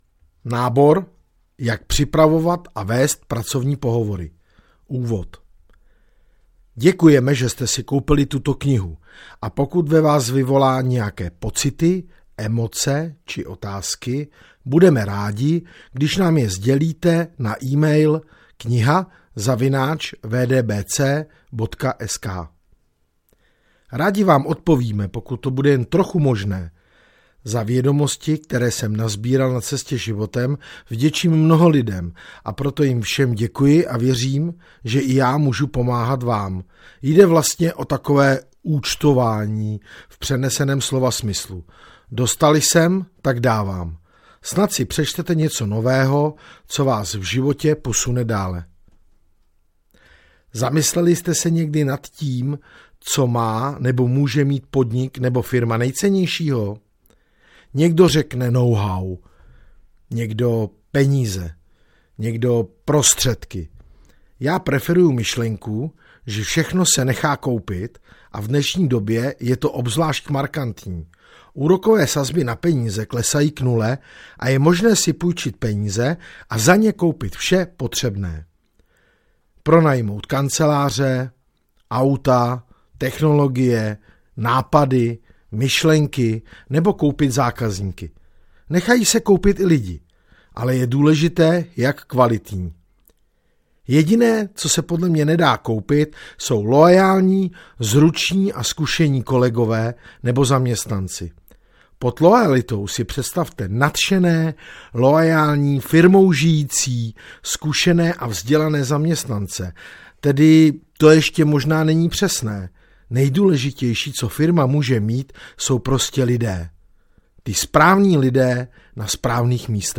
Nábor - Jak vést pracovní pohovory audiokniha
Ukázka z knihy